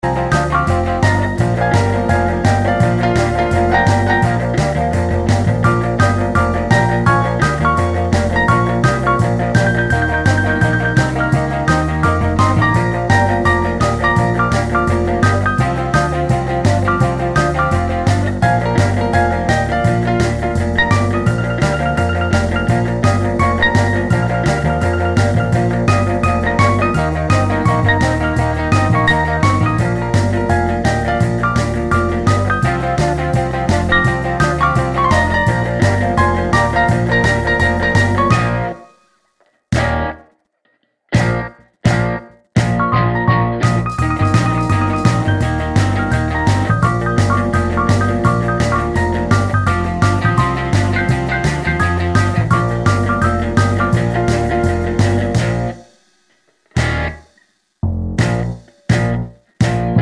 Minus Lead Guitar For Guitarists 2:40 Buy £1.50